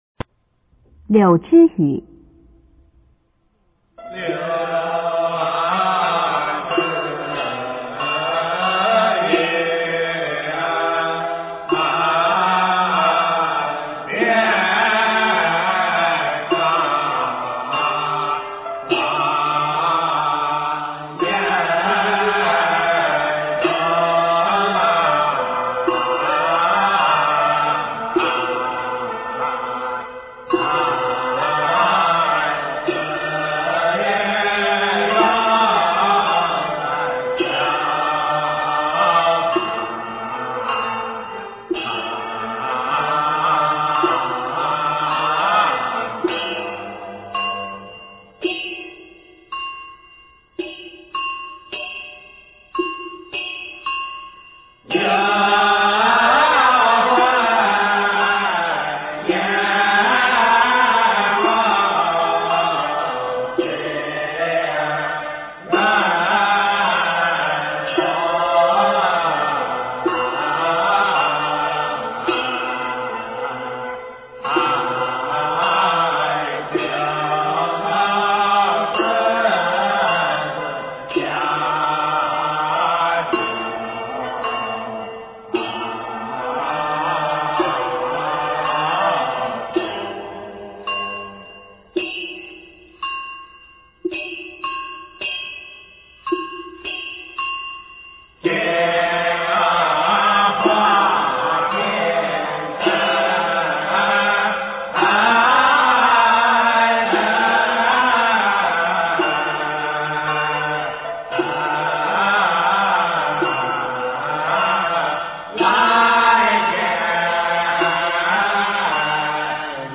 中国道教音乐 全真正韵 柳枝雨